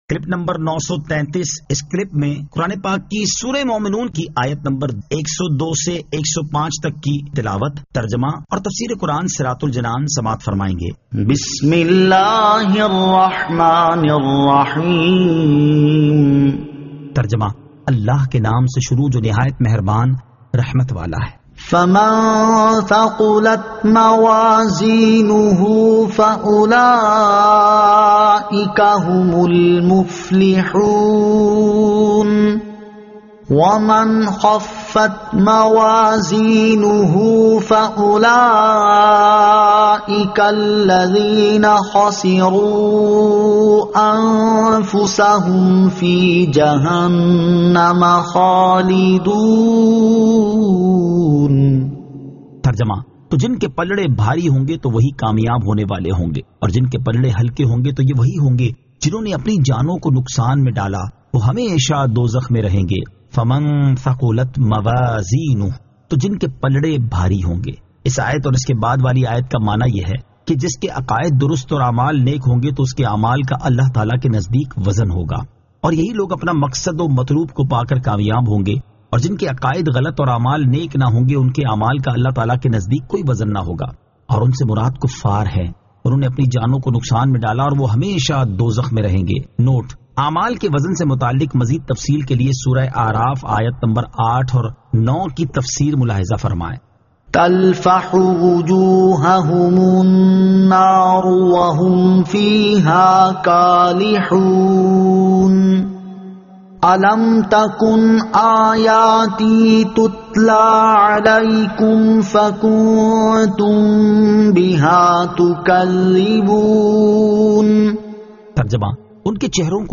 Surah Al-Mu'minun 102 To 105 Tilawat , Tarjama , Tafseer